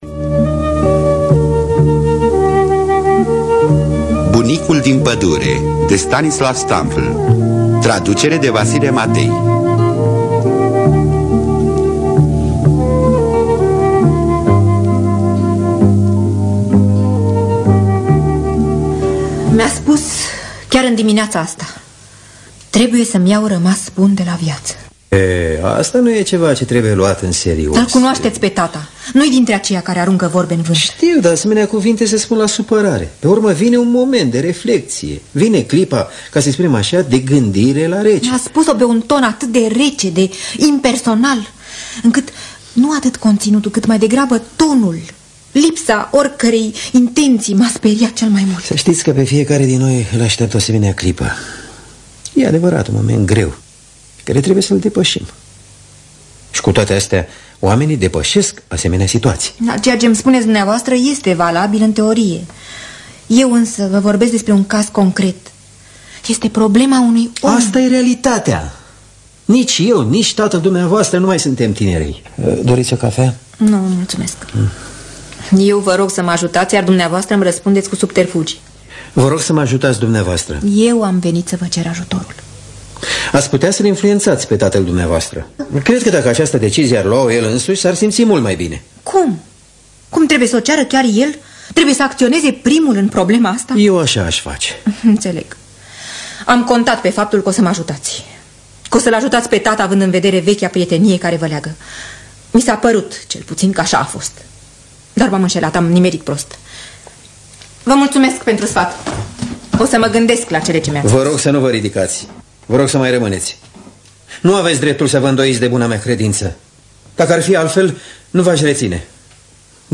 Stanislaw Marian Stampf’l – Bunicul Din Padure (1983) – Teatru Radiofonic Online